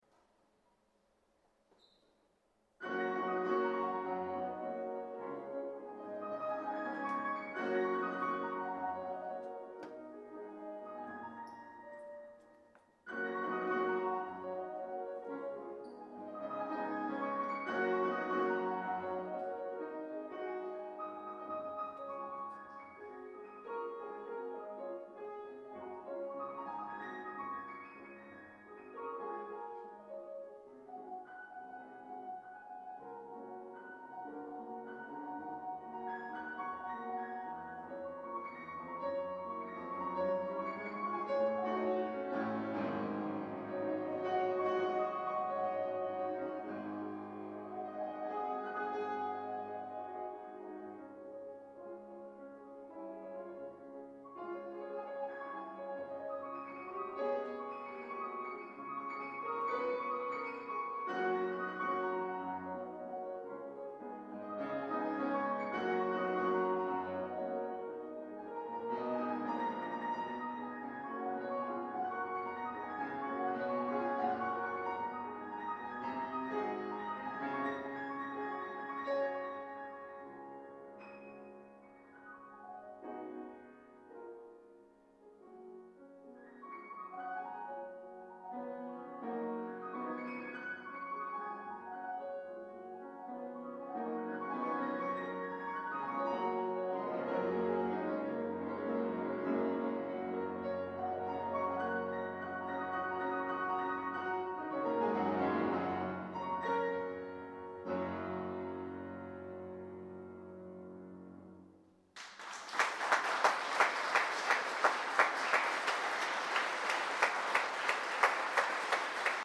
Konzertpianist
• Pianist / Orgelspieler